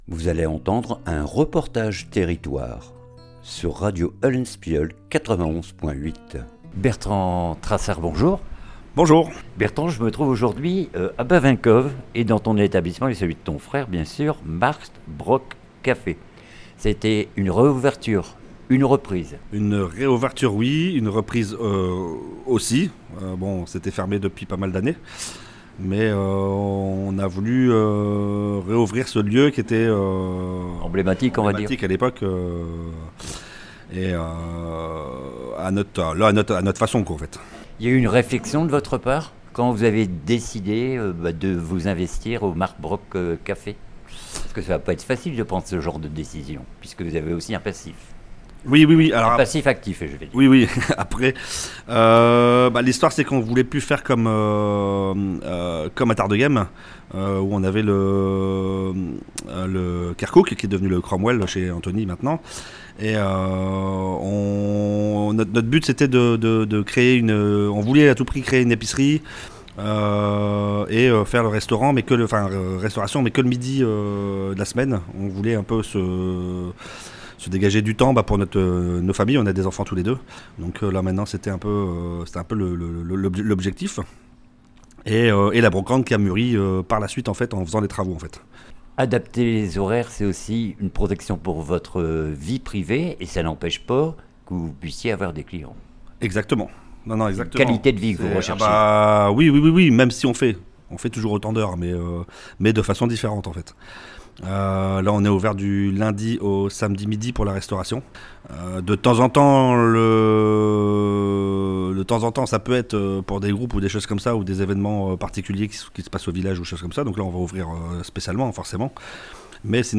REPORTAGE TERRITOIRE MARKT BROC CAFE BAVINCHOVE